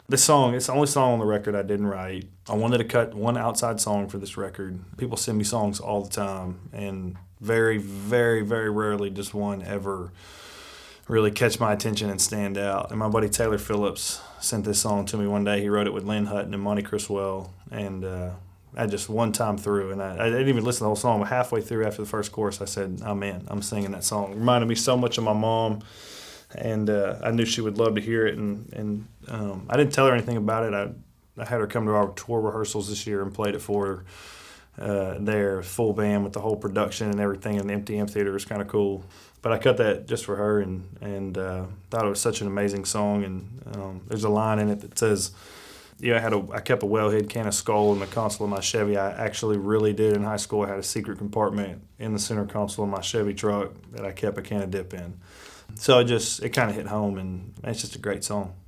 Parker McCollum talks about the song, “Things I Never Told You.”